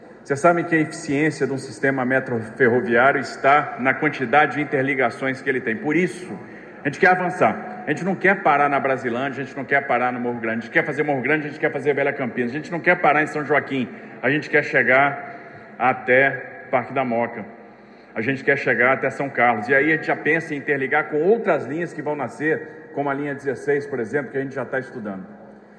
Promessas foram feitas (algumas reafirmadas) pelo secretário de Parcerias em Investimentos, Rafael Benini, e Governador Tarcísio de Freitas, em evento da chegada do Tatuzão até Brasilândia e conclusão de abertura do túnel.
Governador Tarcísio de Freitas: